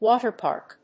音節wáter pàrk